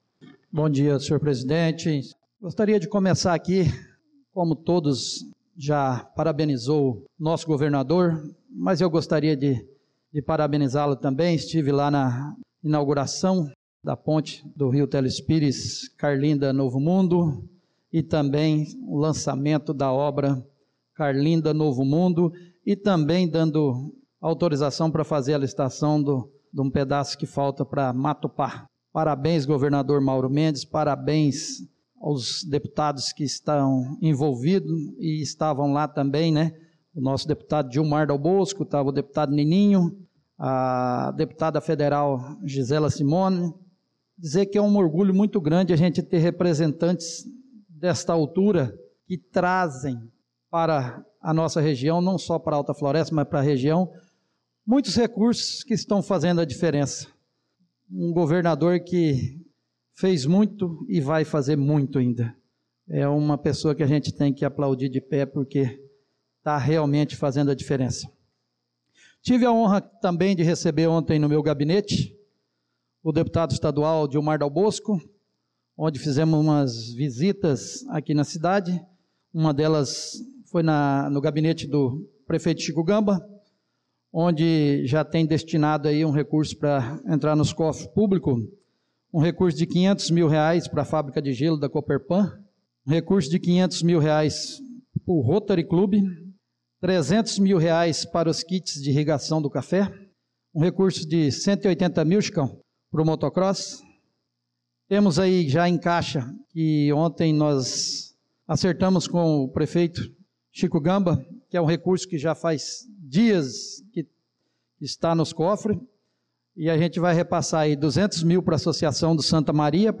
Pronunciamento do vereador Marcos Menin na Sessão Ordinária do dia 25/02/2025